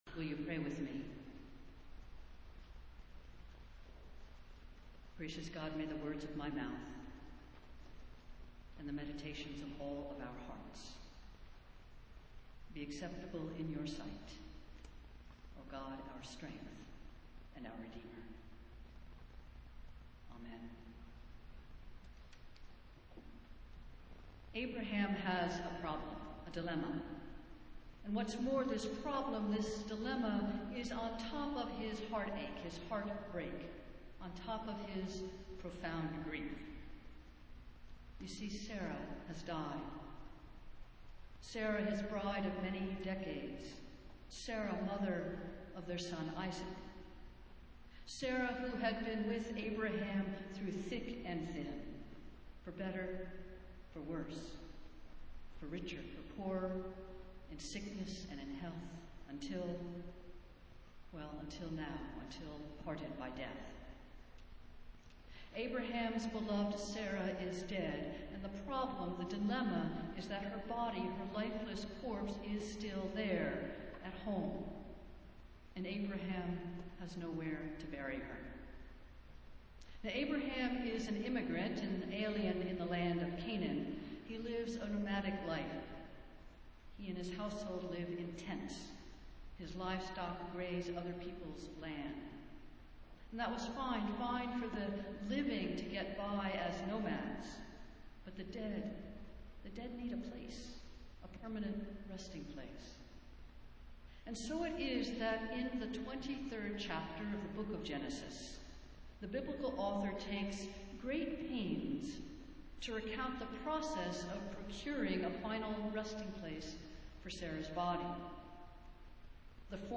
Festival Worship - Sixth Sunday after Pentecost
Preached on the Sunday of the dedication and consecration of the Robert C. & Coley K. Elder Memorial Columbarium